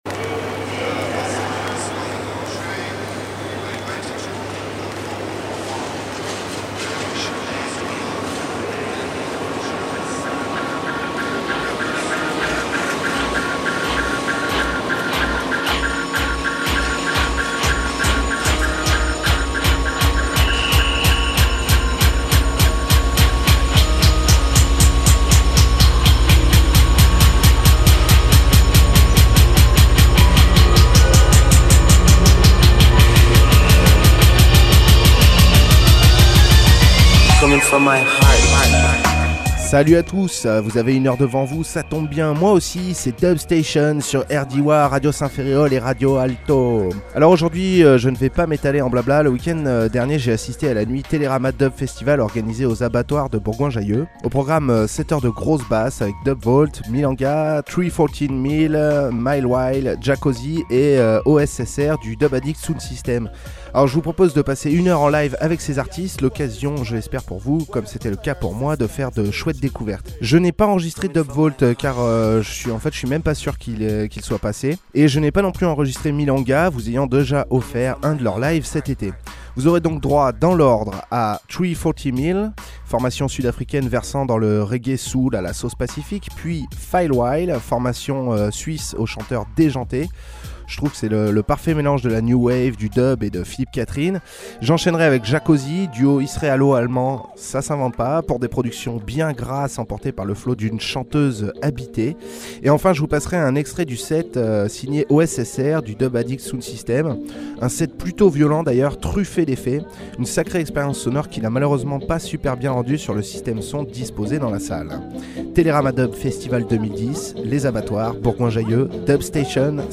reggae/soul chaleureuses
dub aux basses profonde, lorgnant vers le dubstep
une chanteuse au charisme et à la voix incroyable